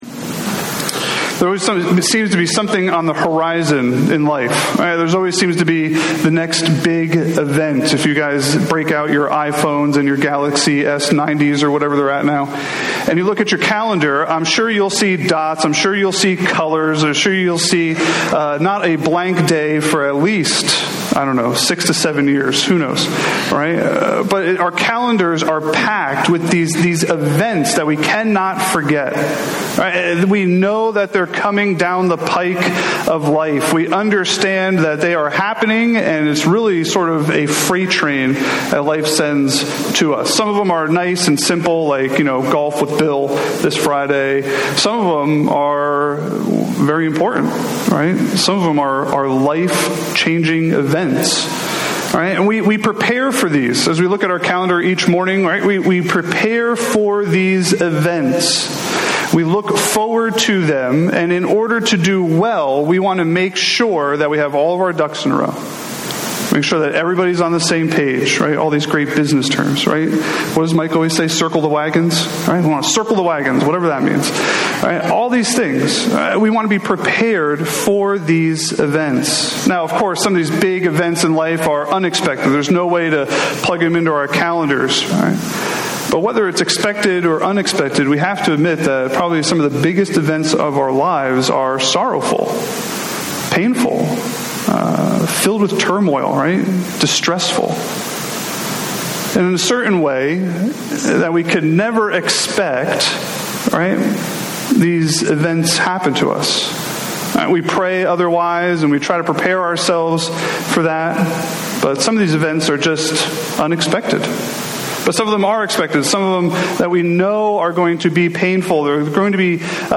A message from the series "Romans."